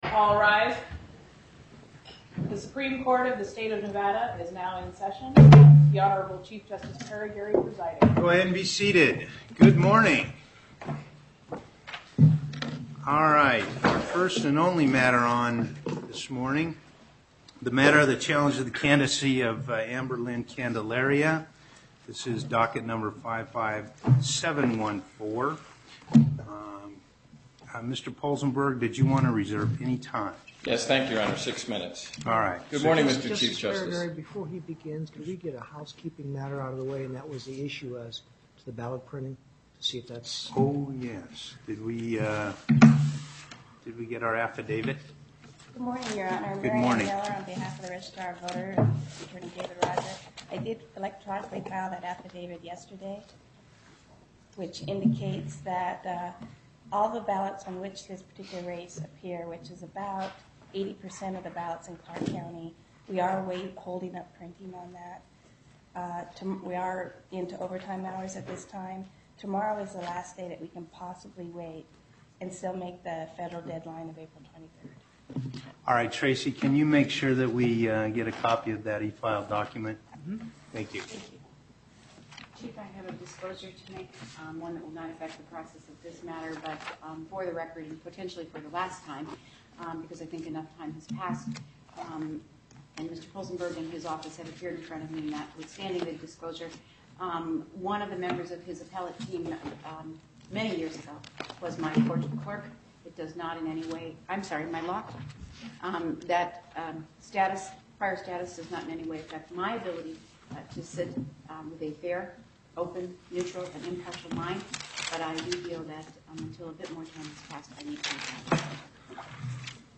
Location: Las Vegas Before the En Banc Court Chief Justice Parraguirre Presiding